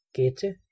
Ääntäminen
IPA: [fɛɐ̯ˈmuːtn̩]